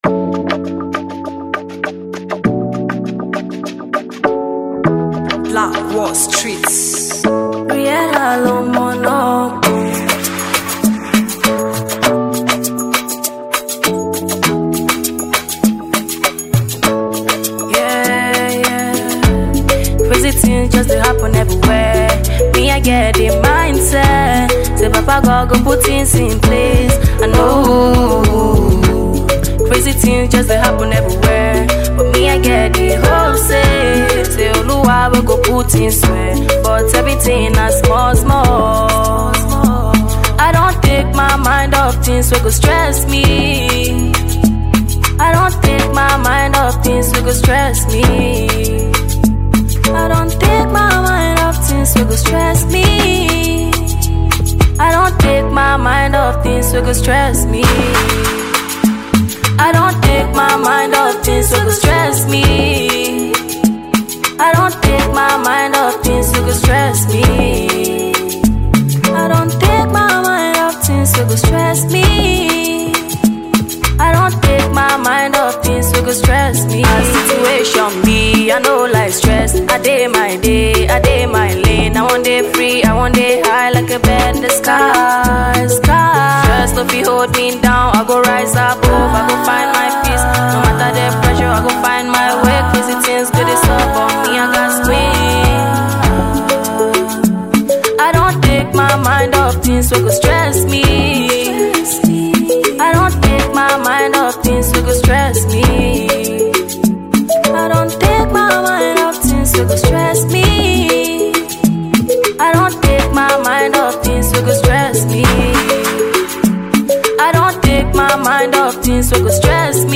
blend of Afrobeat and soul
With her powerful vocals and smooth melodies